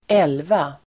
Ladda ner uttalet
Uttal: [²'el:va]